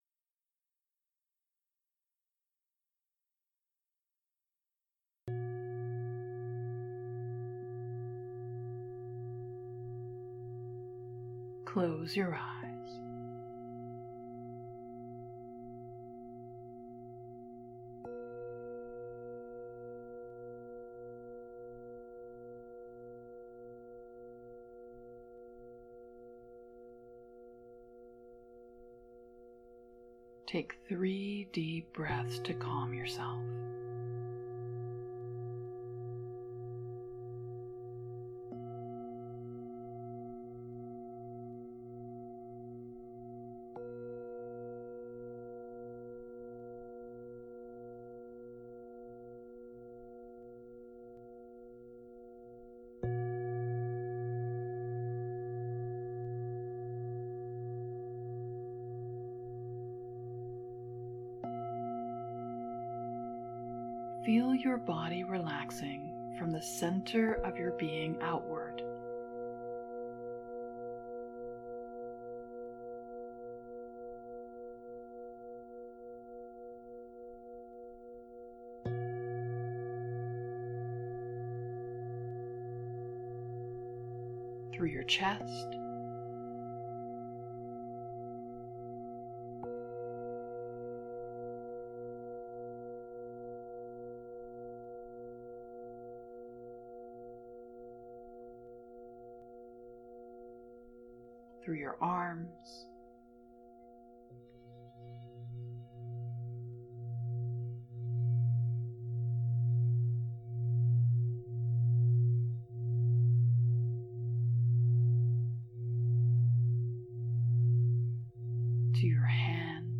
For those who are seeking healing or who just want to know more about what the Akashics has to offer, here is meditation for working with an Akashic Healer.